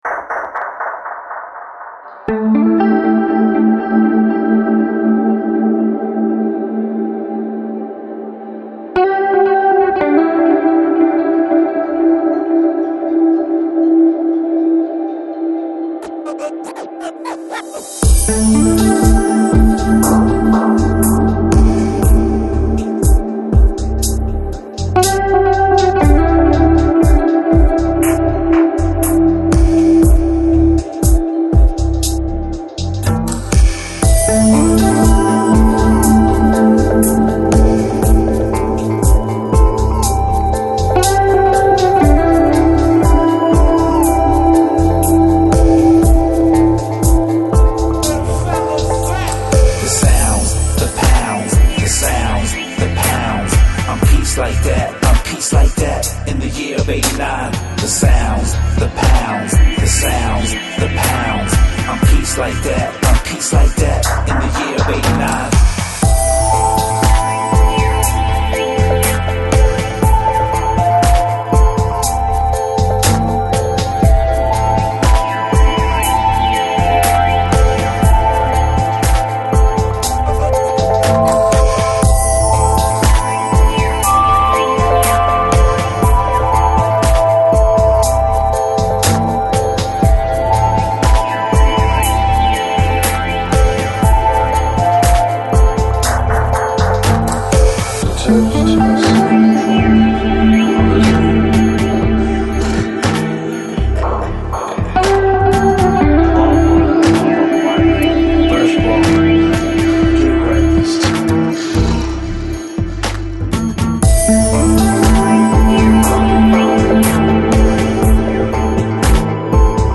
Жанр: Downtempo, Lounge, Chillout